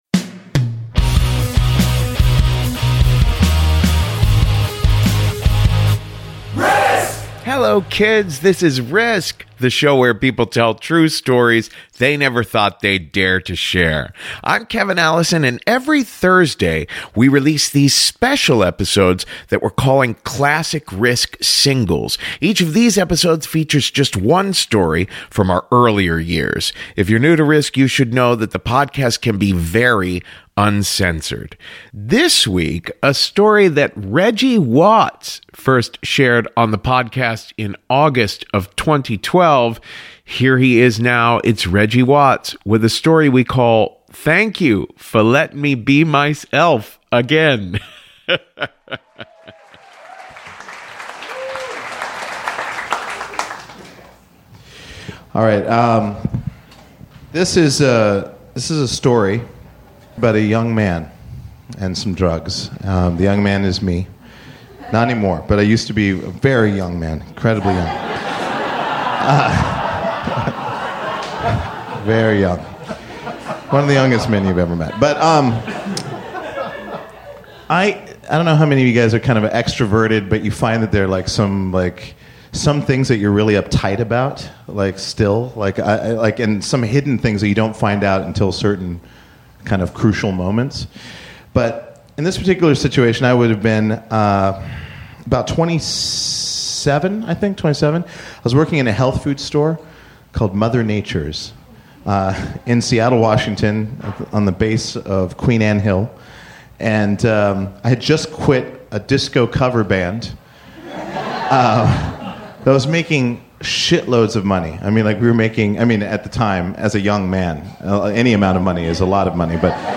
A story that Reggie Watts first shared on the podcast in August of 2012 about a mushroom trip with a friend.